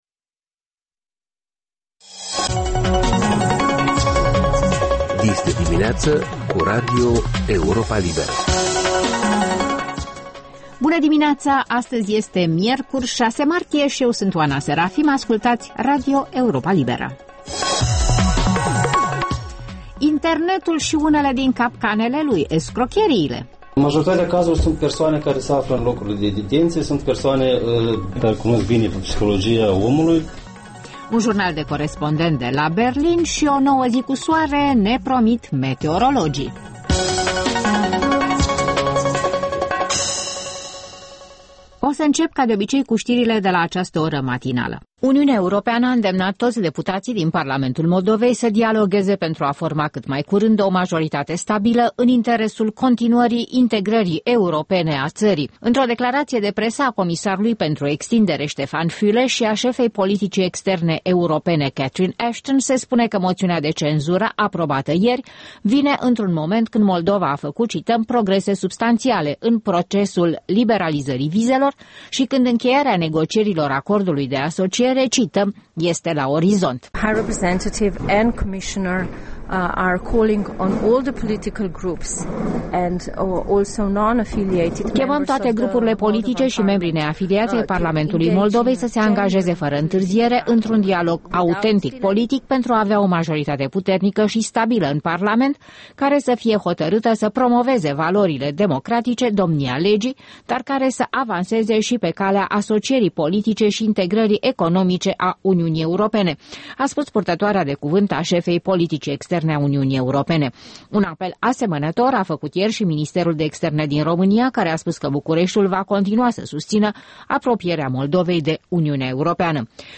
Ştiri, informaţii, interviuri, corespondenţe.